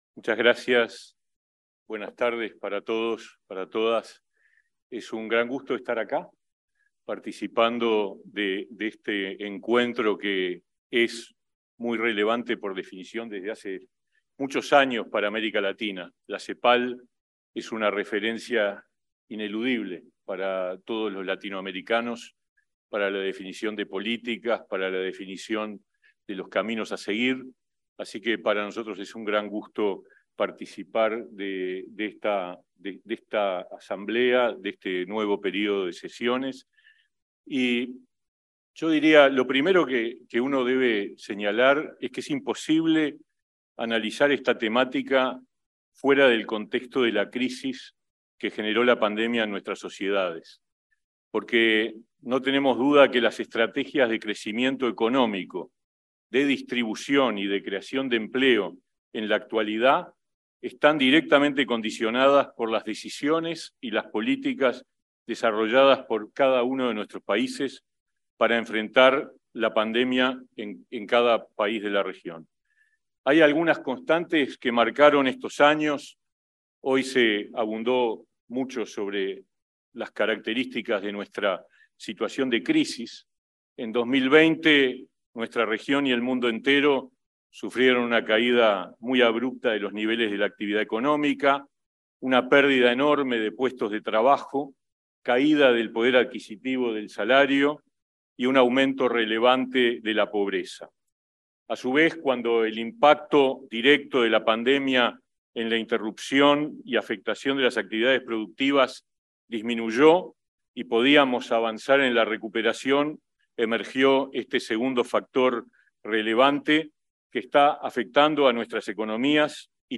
Palabras del ministro de Trabajo y Seguridad Social, Pablo Mieres
El ministro de Trabajo y Seguridad Social, Pablo Mieres, participó, este 24 de octubre, en el 39.° Período de Sesiones de Comisión Económica para